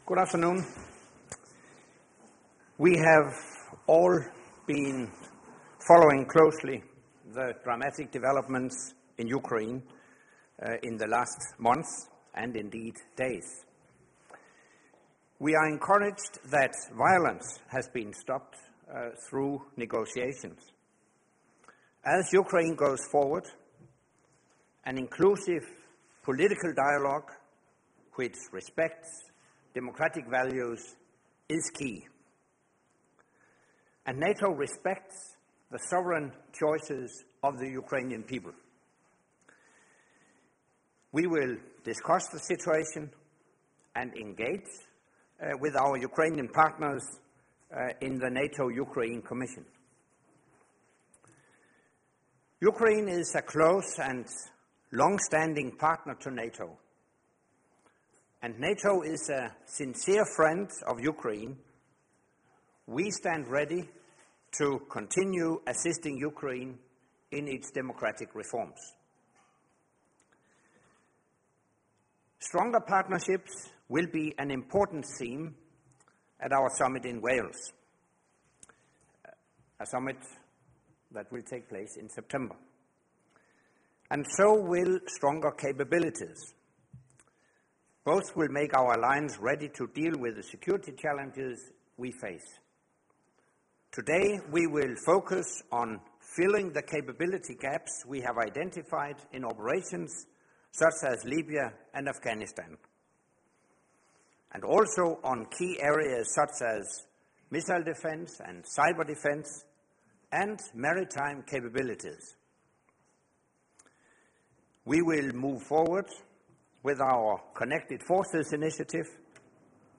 Doorstep statement by the NATO Secretary General at the start of the NATO Defence Ministers meetings
Video Doorstep statement by the NATO Secretary General at the start of the NATO Defence Ministers meetings 26 Feb. 2014 Audio ORIG - Doorstep statement by NATO Secretary General Anders Fogh Rasmussen at the start of the NATO Defence Ministers meeting 26 Feb. 2014 | download mp3